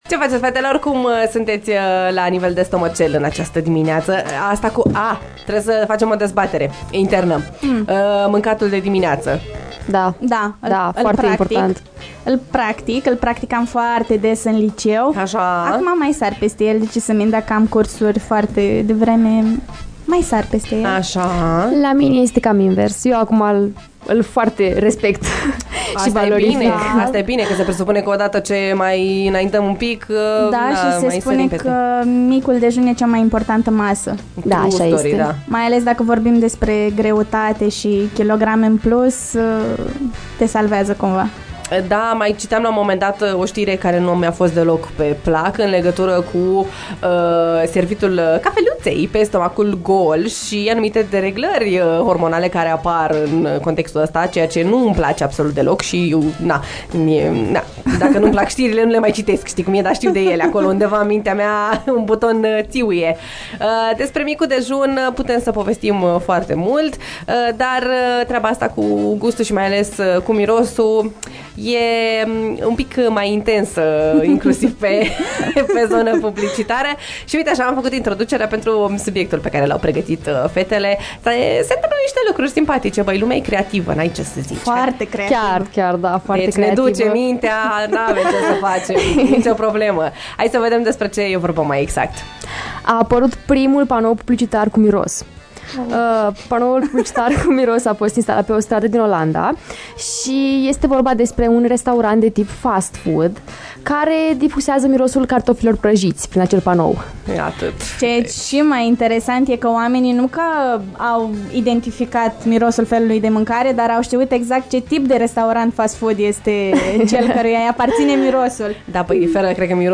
Fetele au discutat, live, despre subiecte variate – de la cățeluși simpatici până la diete echilibrate și hidratare.